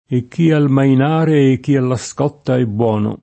mainare v.; maino [ m # ino ]